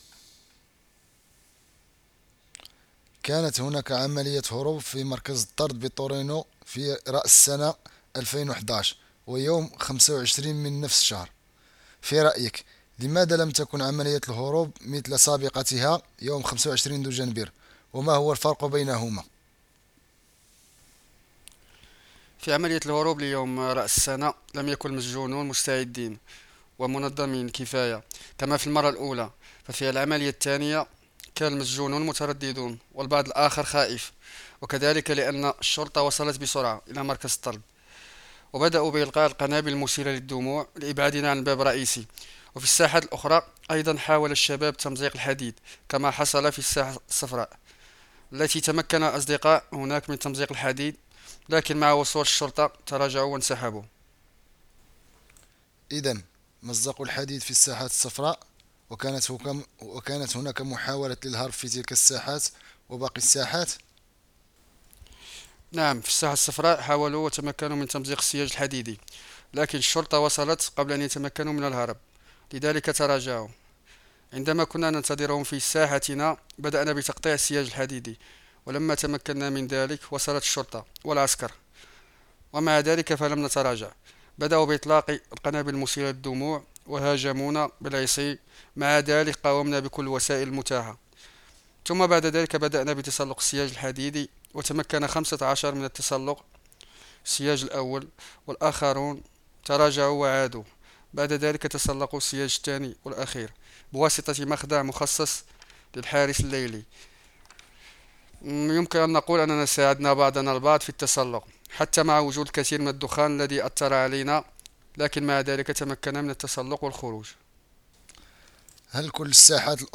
Durante il presidio sarà trasmessa una traduzione in arabo dell’intervista con gli evasi di Natale e Capodanno: